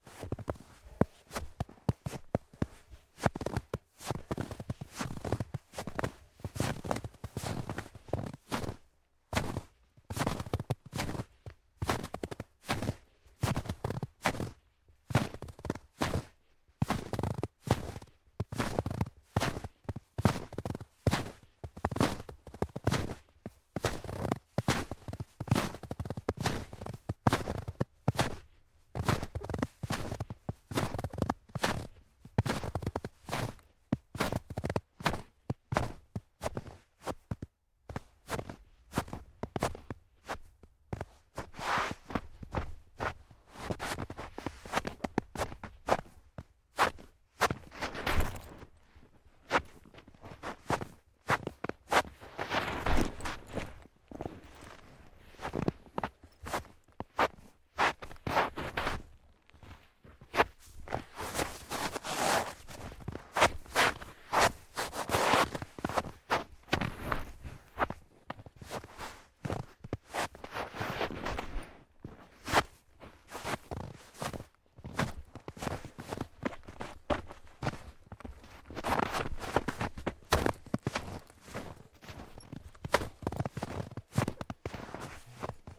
脚踩油毡 " lino 07d leathersandals onoff
描述：在皮毡上打开/关闭皮凉鞋。在房子的地下室用ZOOM H2记录，用Audacity标准化。
标签： 步骤 脚步声 踏板 步骤
声道立体声